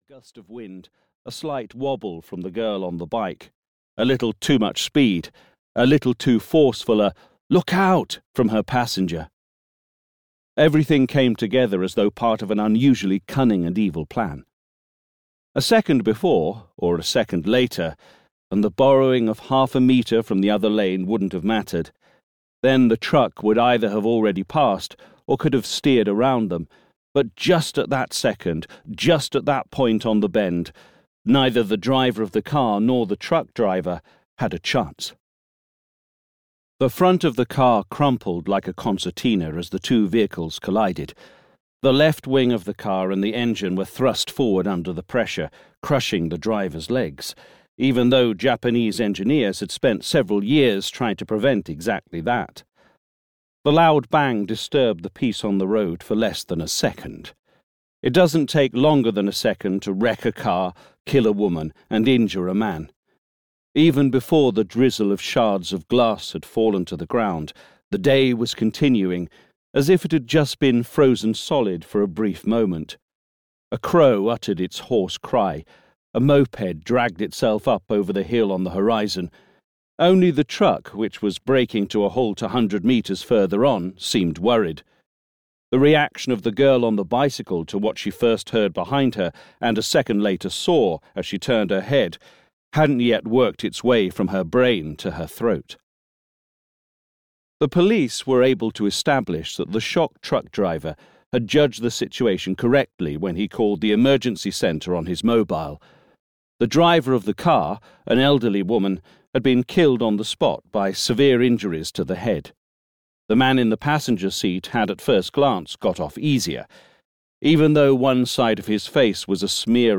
Solitaire (EN) audiokniha
Ukázka z knihy